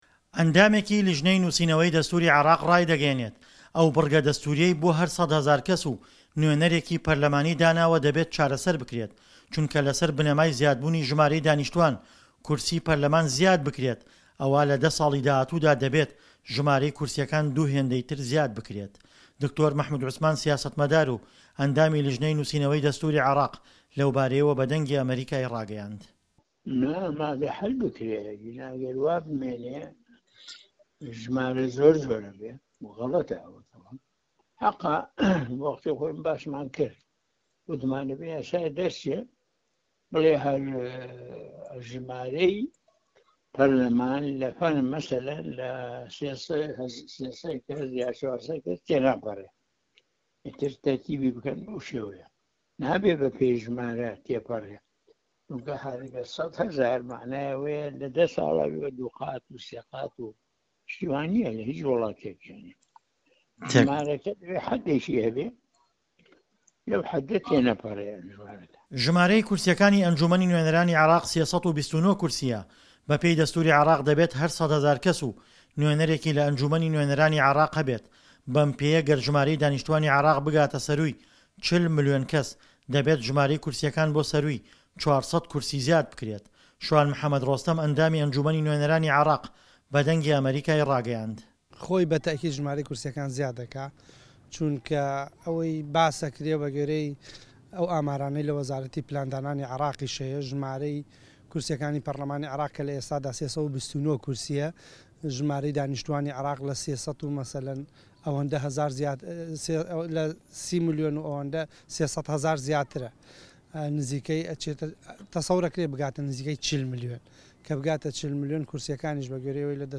ڕاپۆرتی